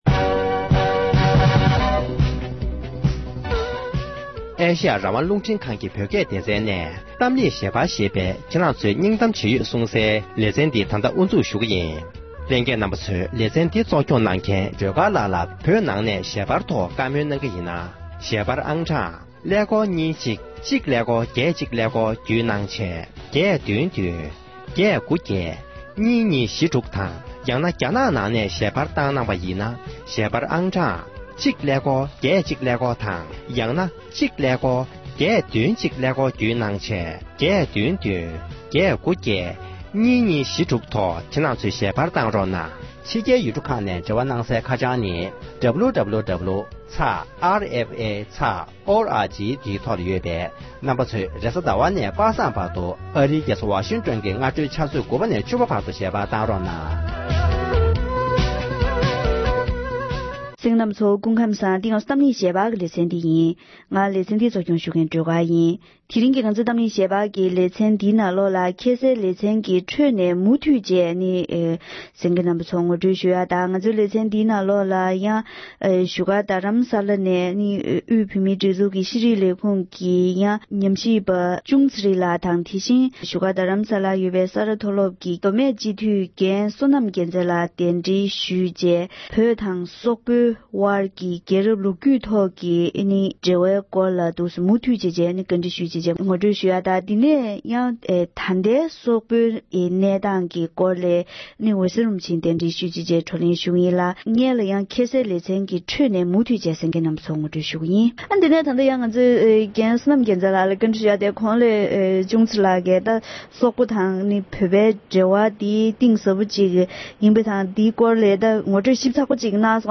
སོག་ཡུལ་གྱི་གནས་སྟངས་དང་དེ་བཞིན་བོད་དང་འབྲེལ་ལམ་གང་འདྲ་ཡོད་མེད་ཐད་འབྲེལ་ཡོད་མི་སྣ་དང་བཀའ་མོལ་ཞུས་པའི་དུམ་བུ་༢པ།